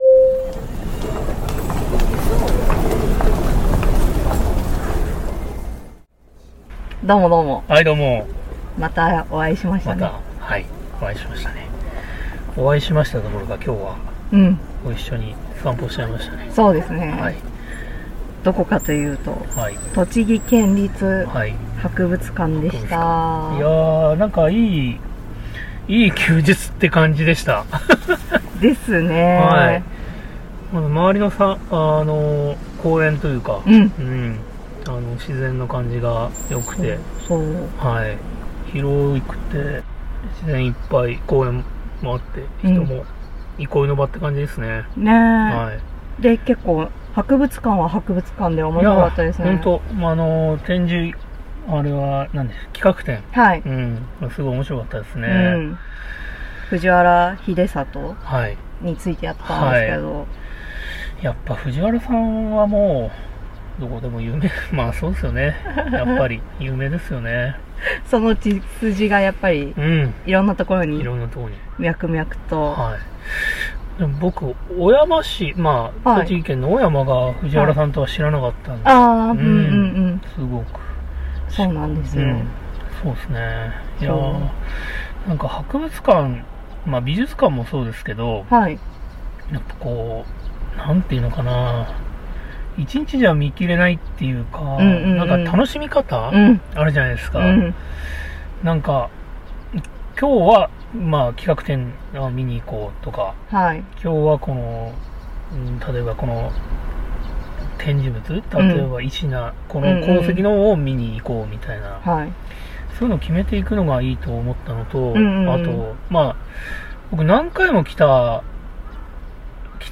今日二人は何やら「旅に必要な能力」の話をしているようです。
Audio Channels: 2 (stereo)